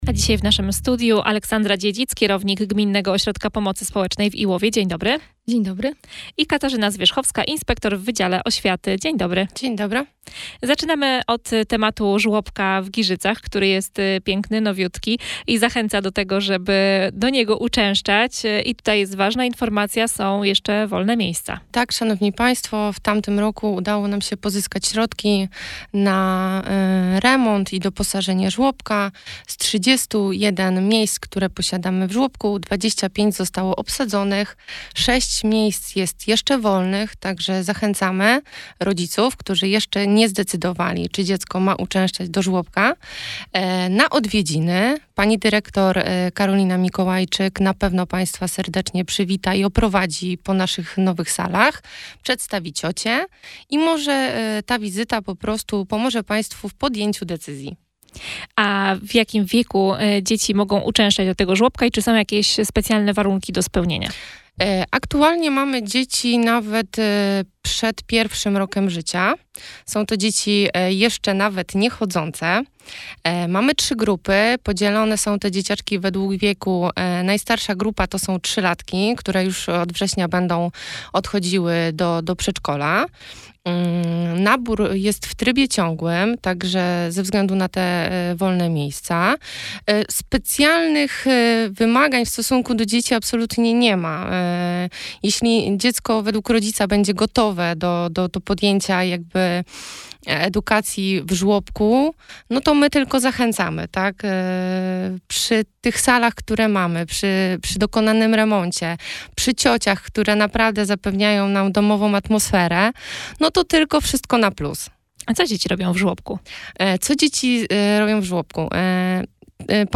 Zapraszamy do wysłuchania wywiadu w Radio Sochaczew